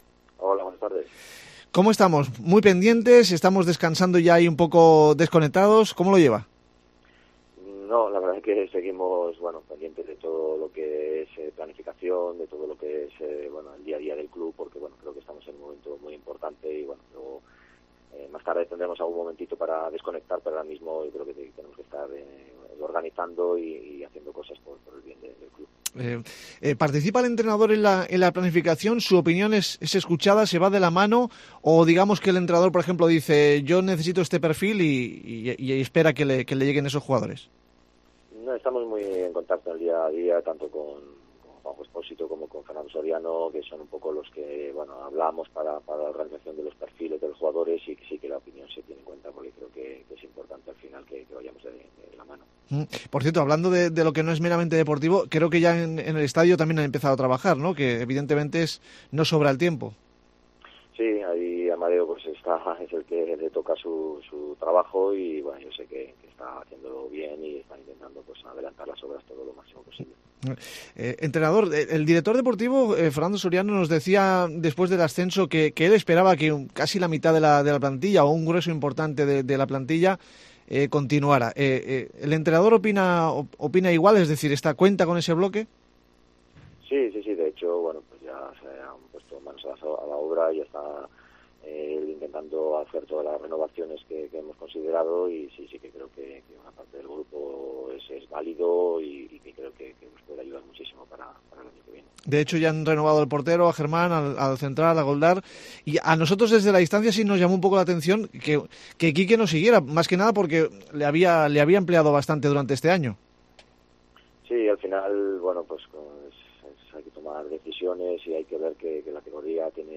Entrevistamos al técnico del histórico ascenso a Segunda División de la UD Ibiza, Juan Carlos Carcedo.